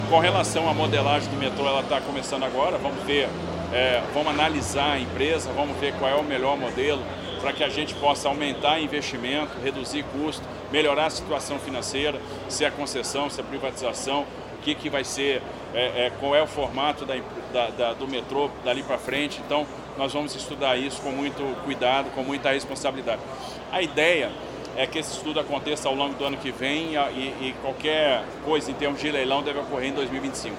A declaração foi dada em uma agenda pública pela manhã em Guarulhos, na Grande São Paulo.